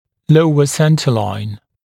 [‘ləuə ‘sentəlaɪn][‘лоуэ ‘сэнтэлайн]центральная линия нижнего зубного ряда